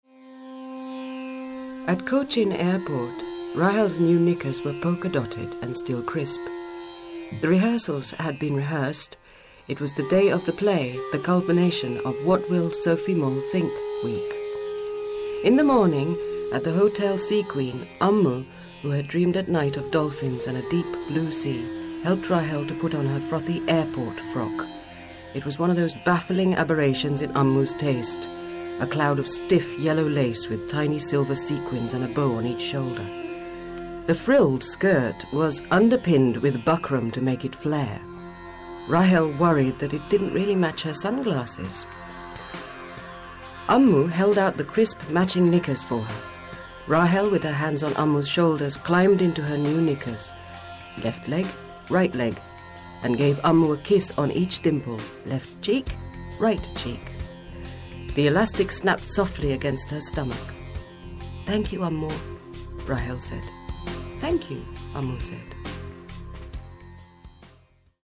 Narration
Audio Book